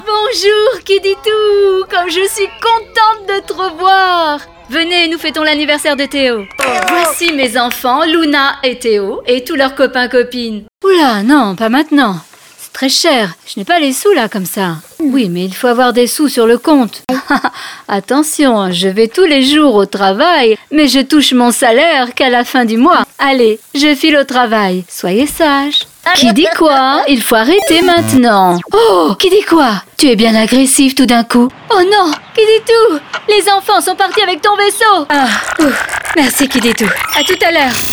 Voix burger du chef